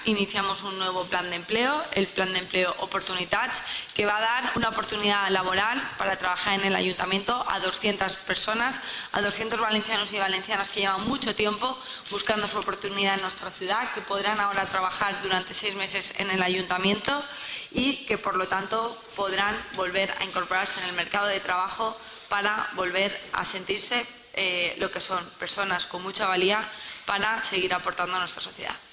• Sandra Gómez ha presentado el programa "Oportunitats" en rueda de prensa.